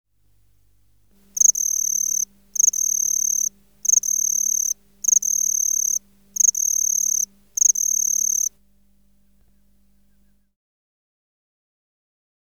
Project: Natural History Museum Sound Archive Species: Teleogryllus (Teleogryllus) xanthoneurus